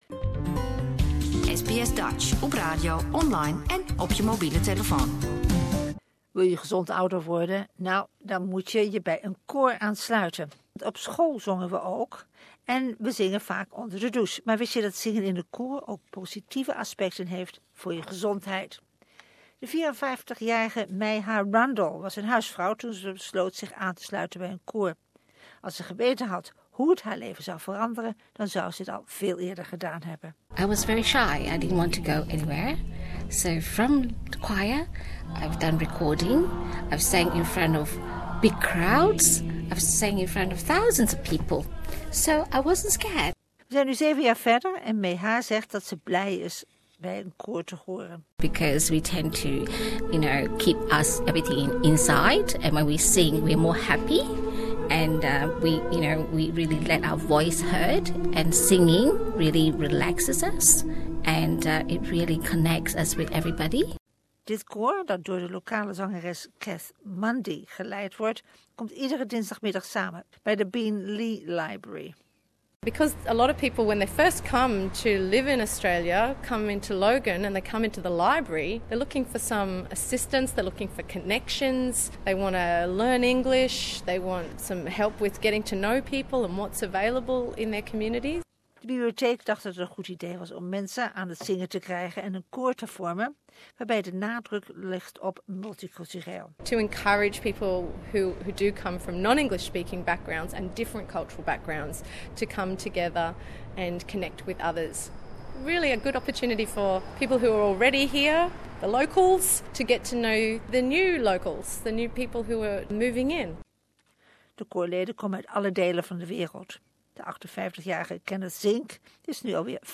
Want to age better? In the second episode of VIVA we visit a multicultural choir practice where they sing in 30 different languages. It cuts through social isolation too!